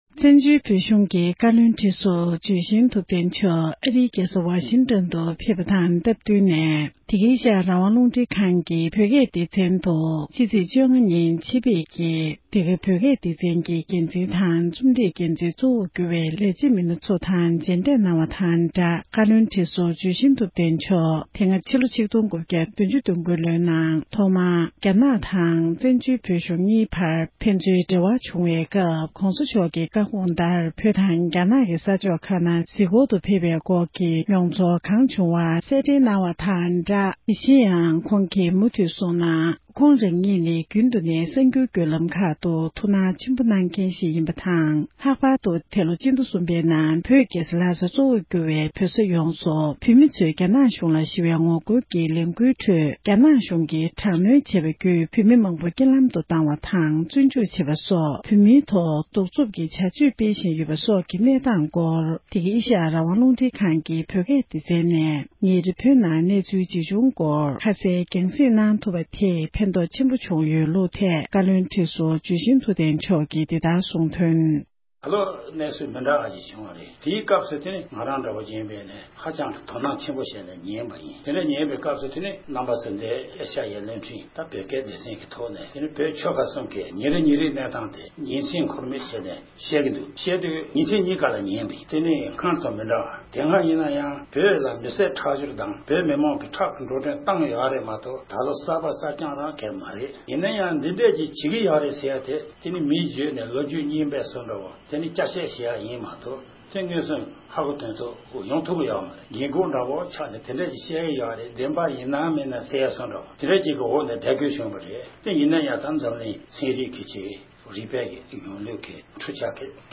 བཀའ་ཟུར་འཇུ་ཆེན་ཐུབ་བསྟན་རྣམ་རྒྱལ་མཆོག་ནས་འདི་ག་རླུང་འཕྲིན་ཁང་དུ་གླེང་མོལ་གསུང་བཤད་གནང་བ།
སྒྲ་ལྡན་གསར་འགྱུར།